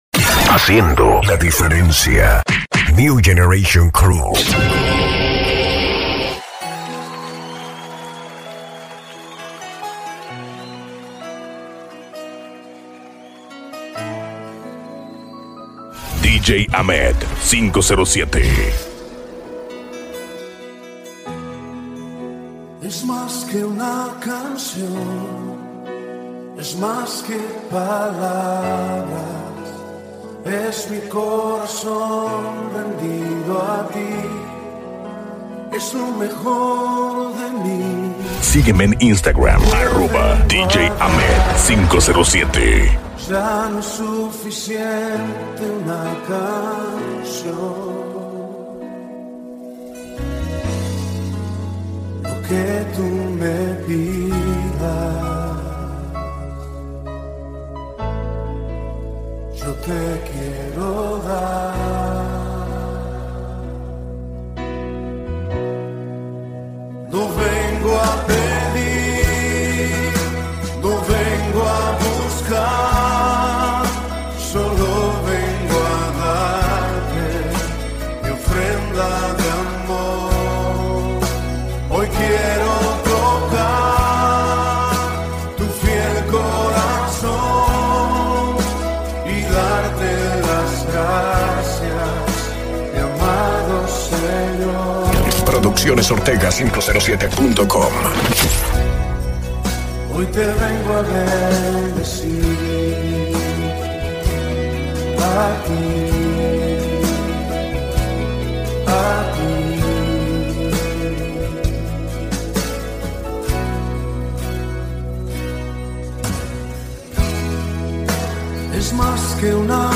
Alabanzas, Mixes